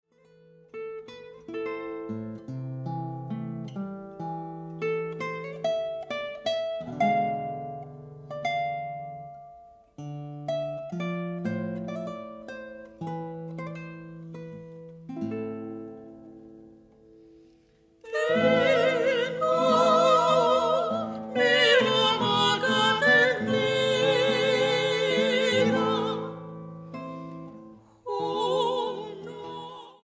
guitarras